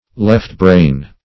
Search Result for " left-brain" : The Collaborative International Dictionary of English v.0.48: left-brain \left"-brain`\, a. Of or pertaining to the left cerebral hemisphere of the brain.